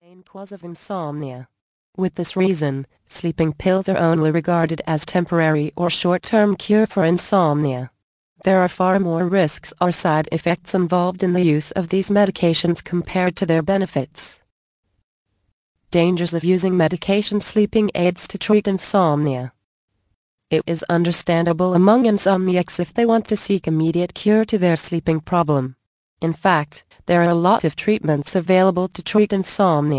The Magic of Sleep audio book Vol. 9 of 14, 71 min.